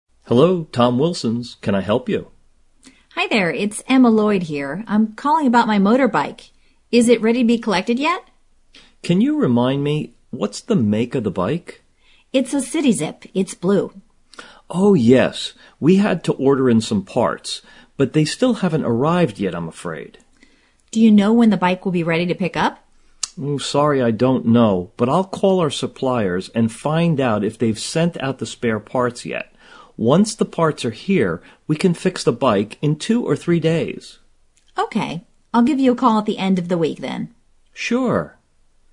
Where is the woman calling ?
2: What information does the man ask for ?